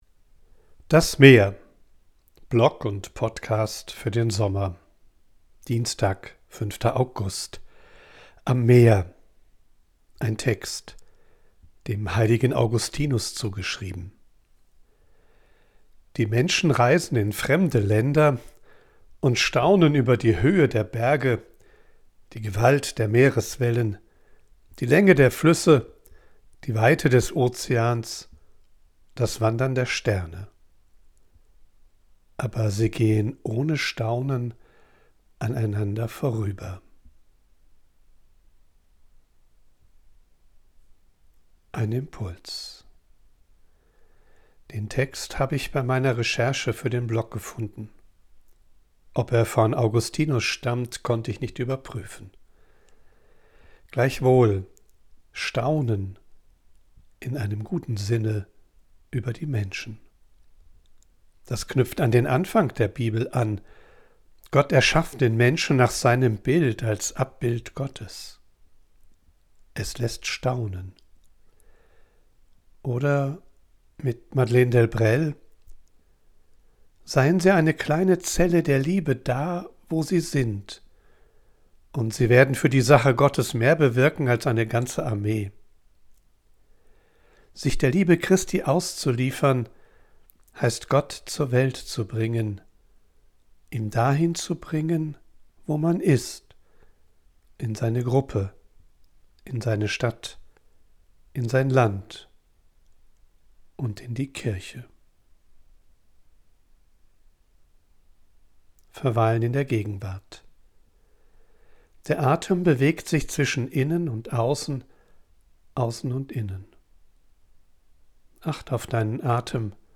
Ich bin am Meer und sammle Eindrücke und Ideen.
von unterwegs aufnehme, ist die Audioqualität begrenzt.
mischt sie mitunter eine echte Möwe und Meeresrauschen in die